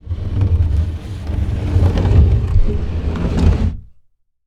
SlidingBookcase